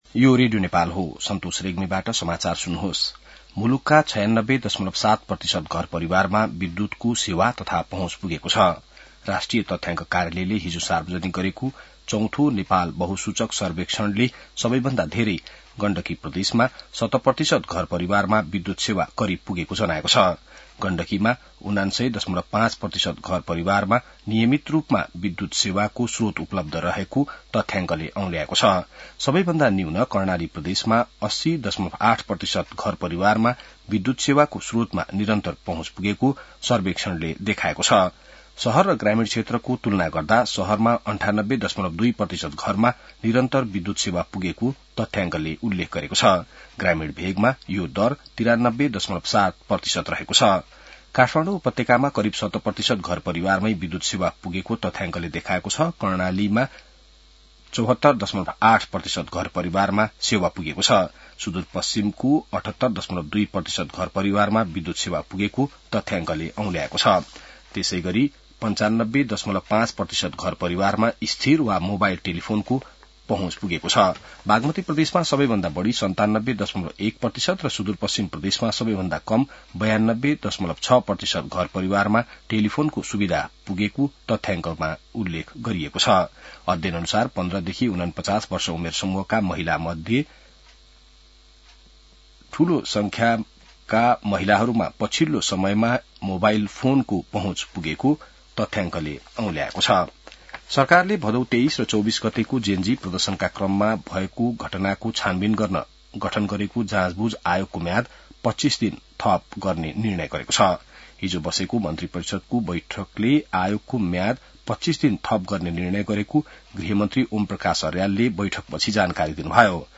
बिहान ६ बजेको नेपाली समाचार : २७ माघ , २०८२